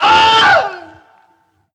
Wilhelm Scream Alternative 5
Category 🗣 Voices
death fall falling famous killed legend legendary male sound effect free sound royalty free Voices